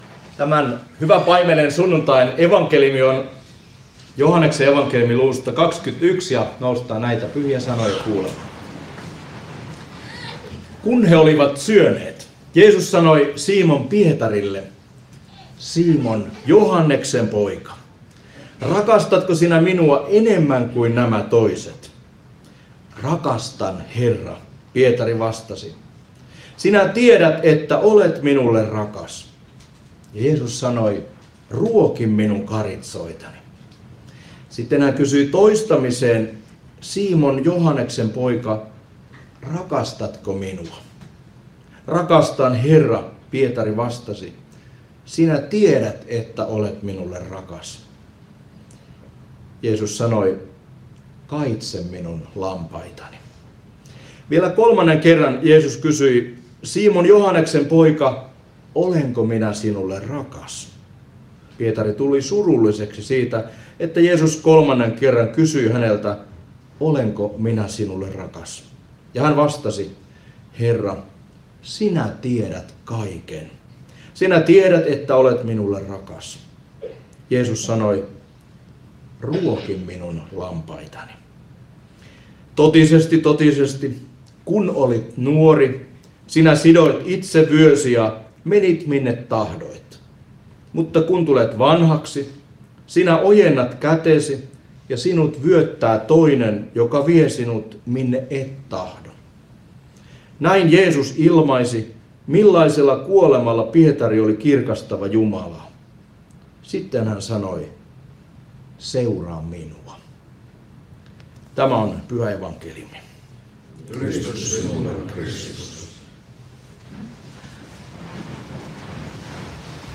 Pori